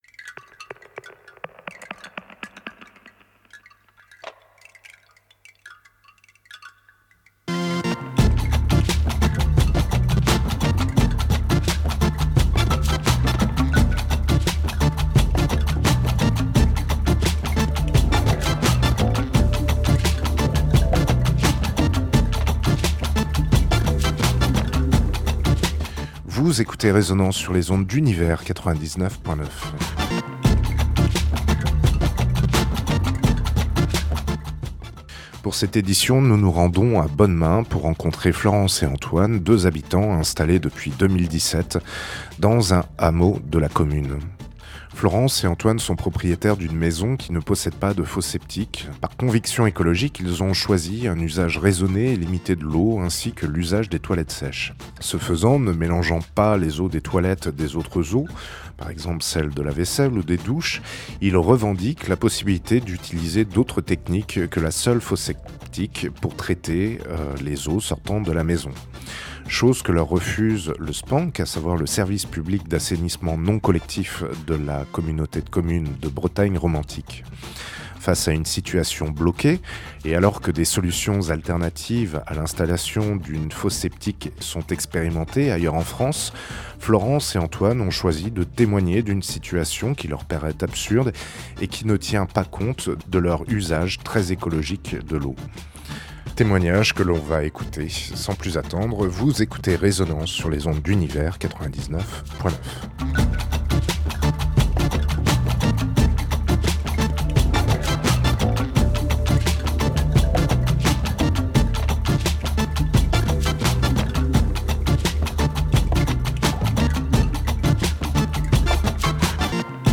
Entretien autour de l’assainissement non-collectif et des usages écologiques de l’eau.